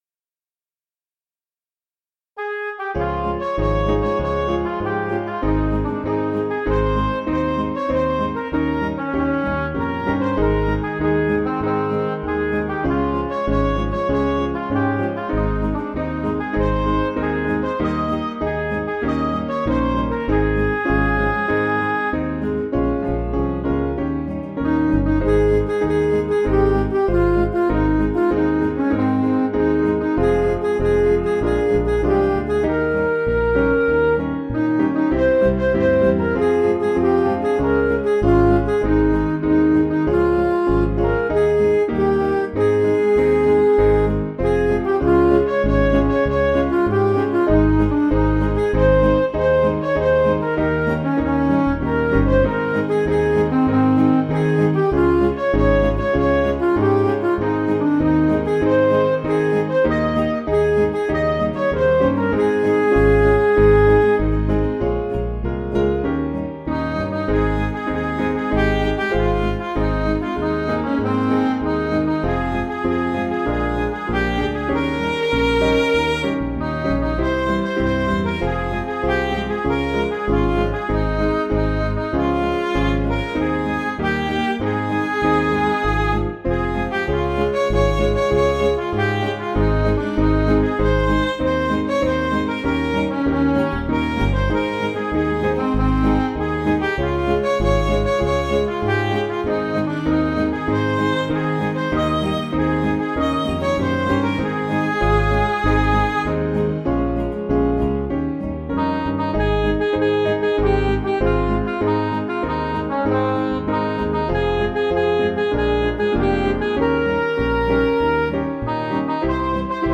Piano & Instrumental
(CM)   4/Ab
Midi